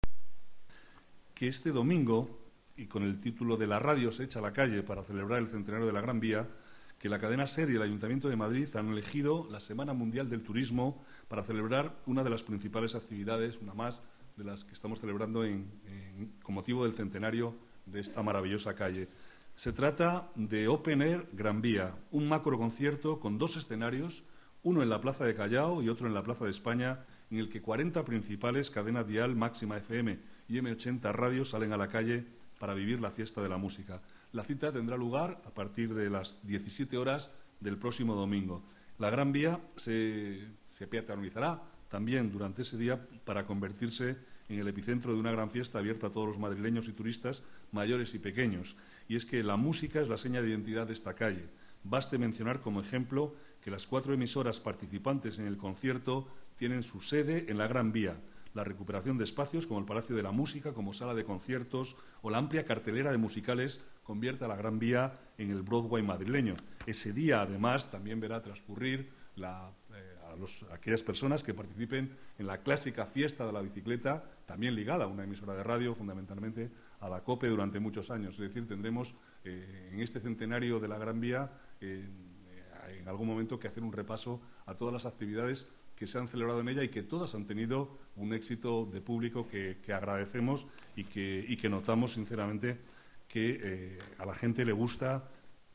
Nueva ventana:El vicealcalde, Manuel Cobo, habla del 'Open Air' que se celebrará en Gran Vía